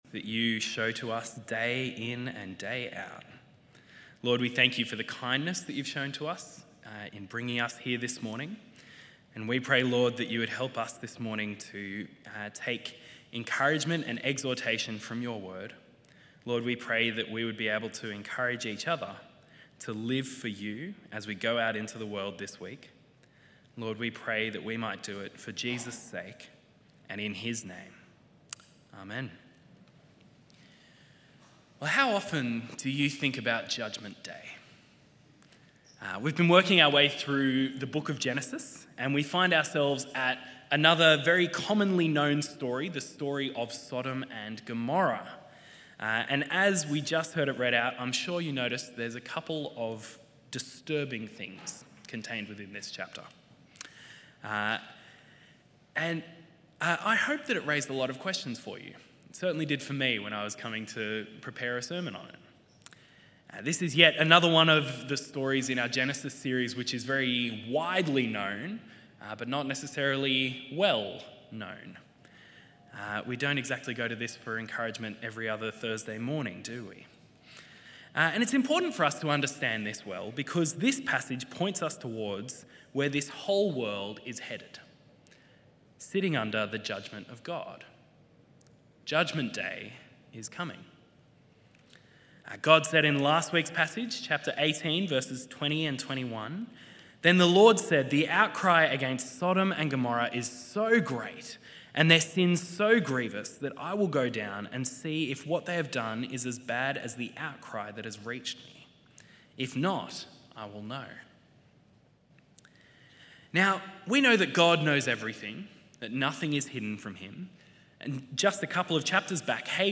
This sermon reflects on the story of Sodom and Gomorrah in Genesis, exploring themes of judgment, sin, and God's mercy. It begins with a prayer of gratitude and a plea for encouragement from God's word.